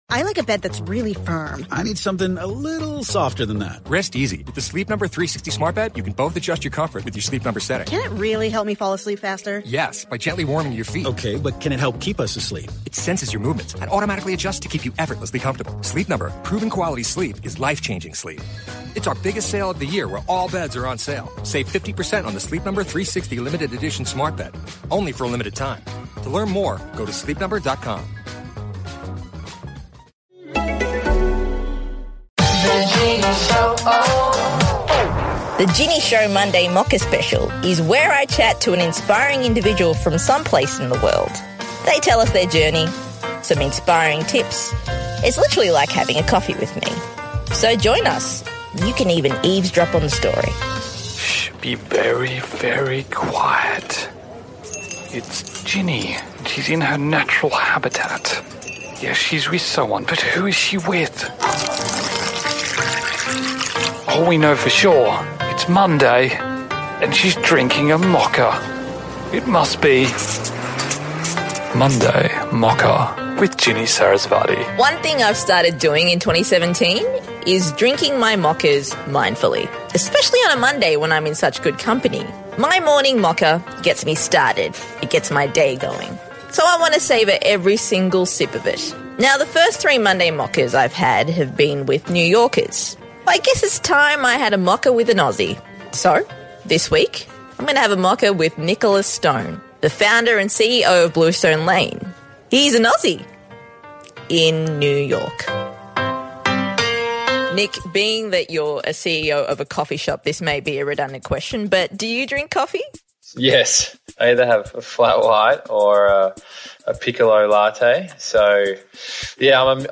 Each Monday, I'll be having a chat to a guest who has much wisdom to share that we can all benefit from - to light up our day that little bit brighter. It will literally feel like you're sitting right there, having coffee with us.